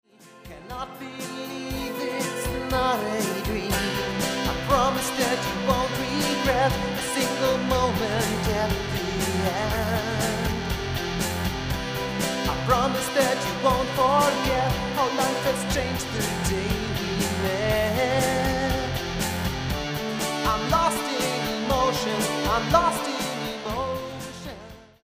vocals